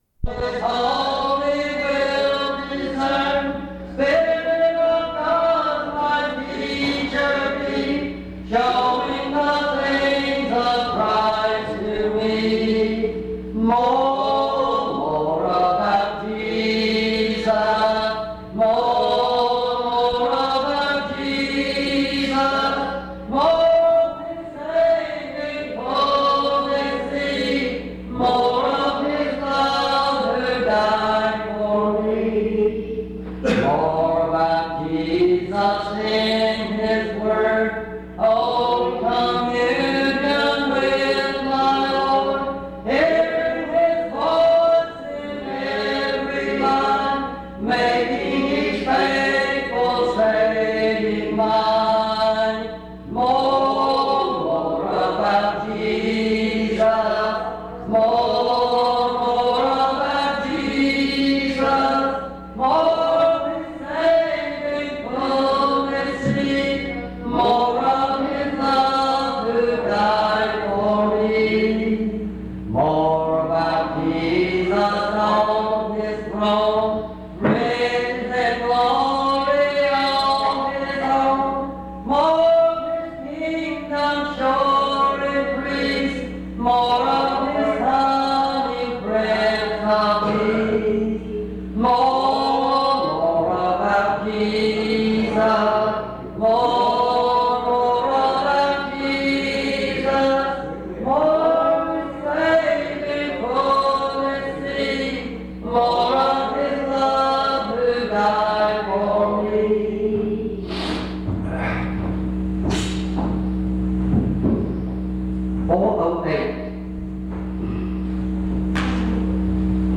In Collection: Reidsville/Lindsey Street Primitive Baptist Church audio recordings Miniaturansicht Titel Hochladedatum Sichtbarkeit Aktionen PBHLA-ACC.001_071-B-01.wav 2026-02-12 Herunterladen PBHLA-ACC.001_071-A-01.wav 2026-02-12 Herunterladen